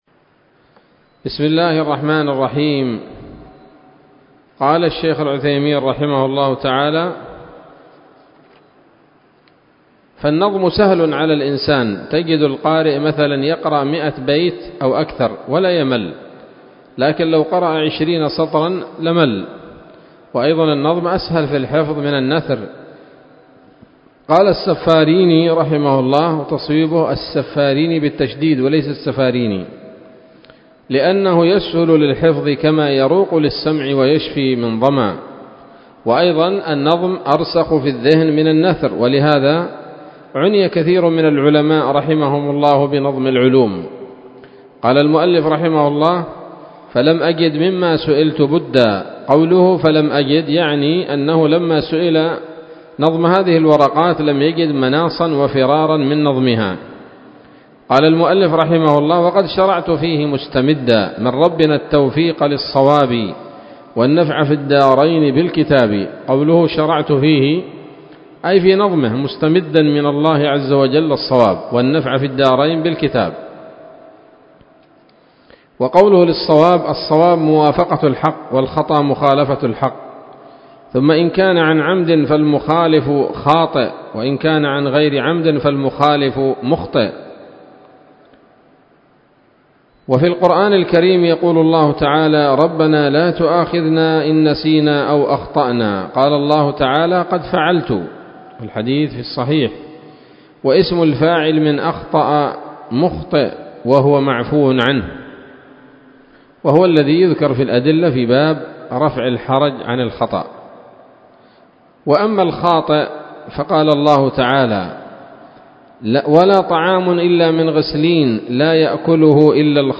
الدرس الرابع من شرح نظم الورقات للعلامة العثيمين رحمه الله تعالى